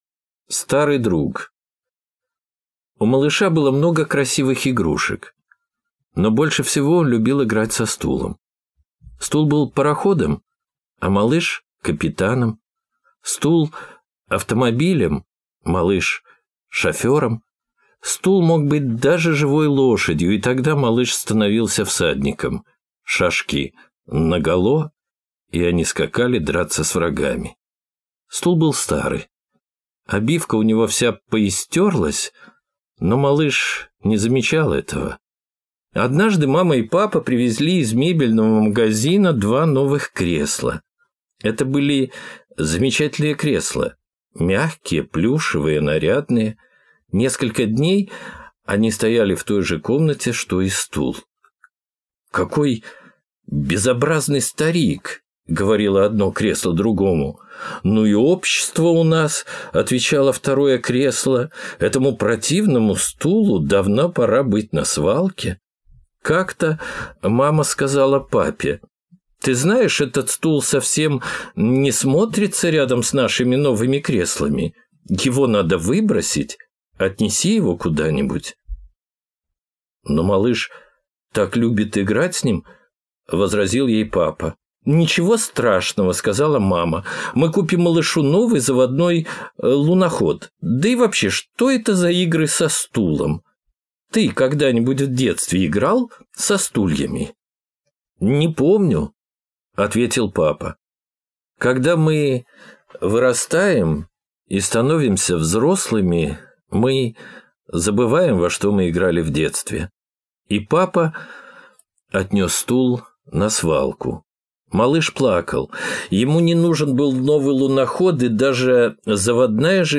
Старый друг - аудиосказка Романа Темиса - слушать онлайн